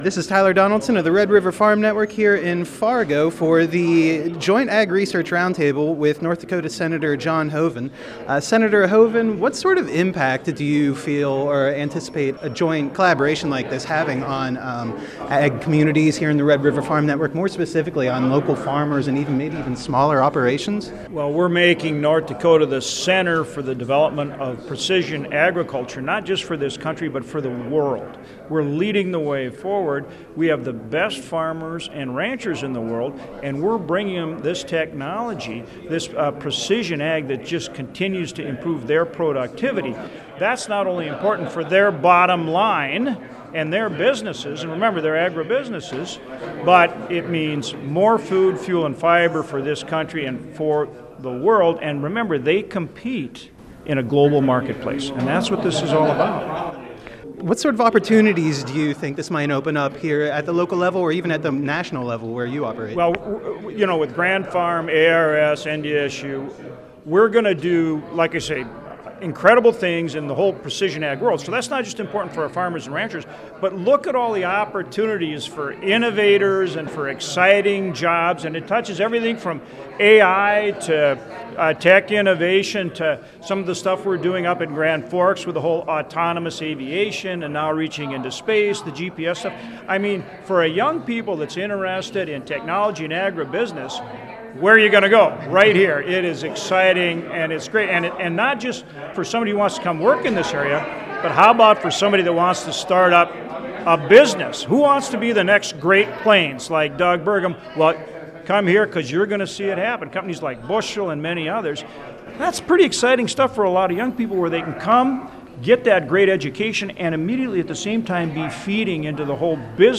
At a roundtable session in Fargo on Friday, North Dakota Senator John Hoeven discussed opportunities for agriculture research in North Dakota and leveraging initiatives, such as the Food systems Adapted for Resiliency and Maximized Security (FARMs) project and the AgTech Cooperative Agreement.